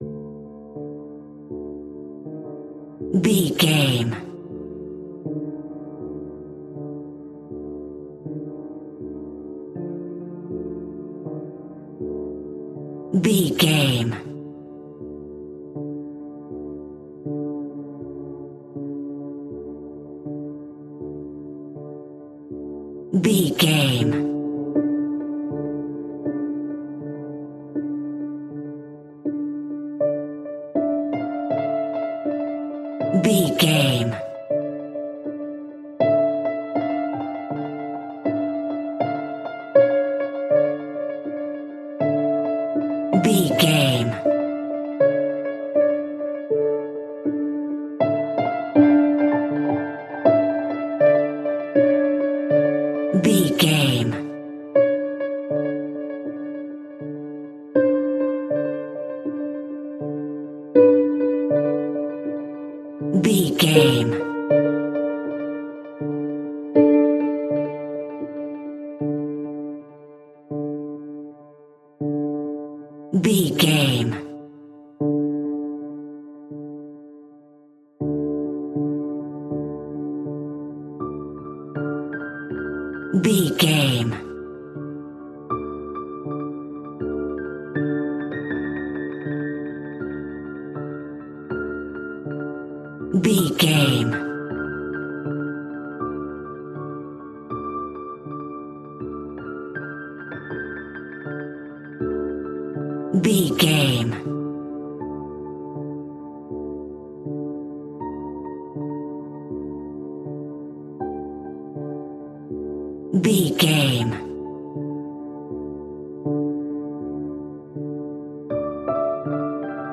Ionian/Major
suspense
synthesiser